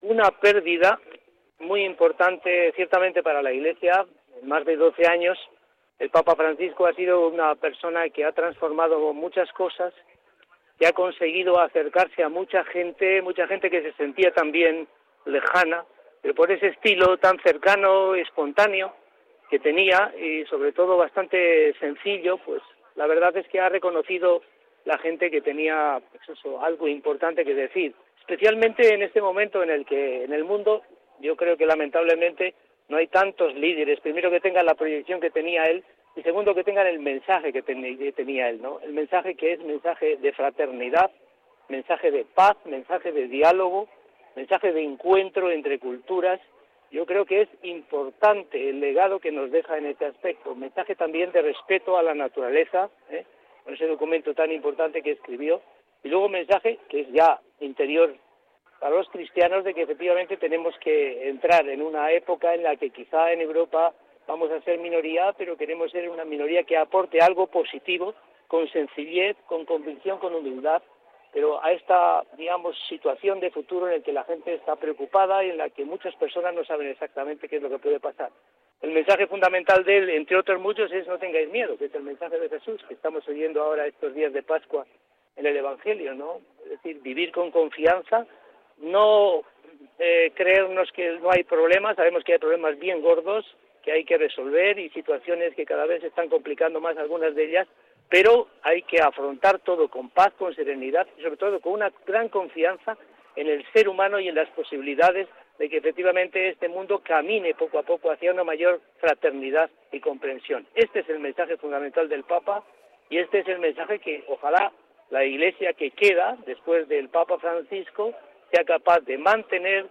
Declaraciones del obispo Joseba Segura por la muerte del Papa
Segura ha comparecido ante los medios una vez concluida la misa.